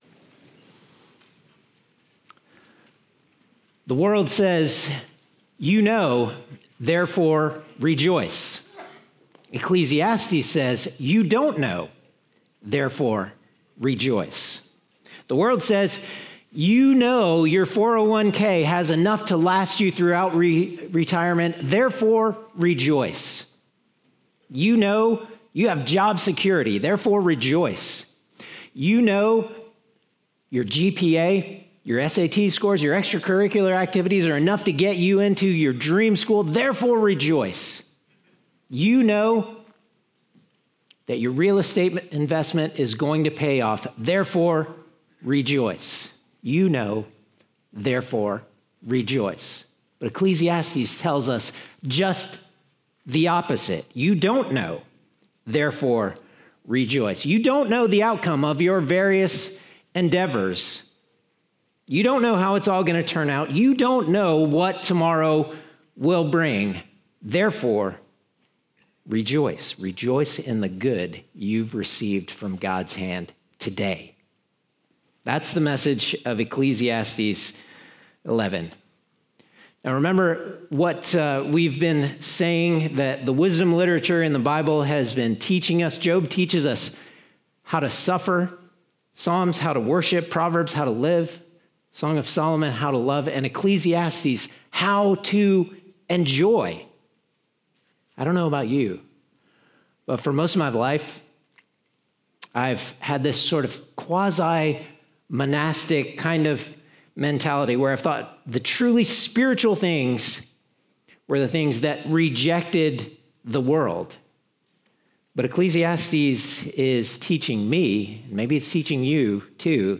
Naperville Presbyterian Church Sermons Podcast - Ecclesiastes 11:1-10 | Free Listening on Podbean App